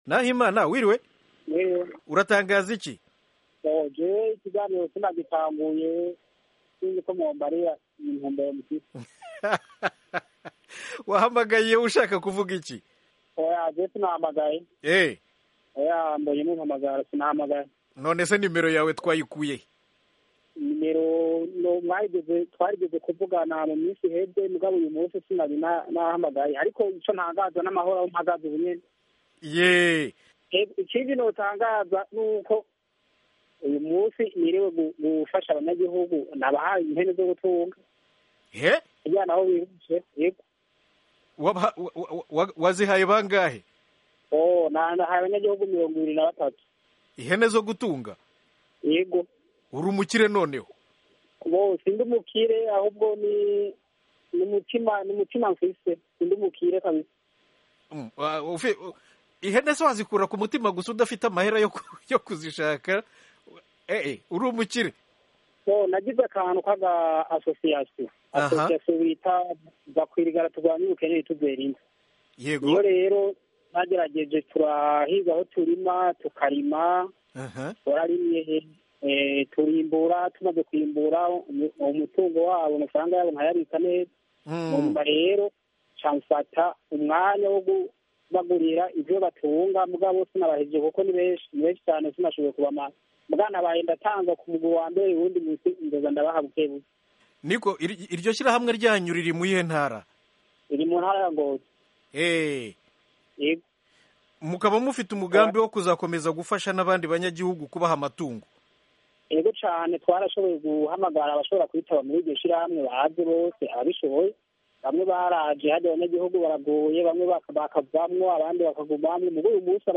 Kuwa gatandatu, taliki ya 19 y’uku kwezi kwa 11, yatanze ihene zo korora 23. Umviriza ikiganiro yagiranye n’Ijwi ry’Amerika Kwiteza imbere i Ngozi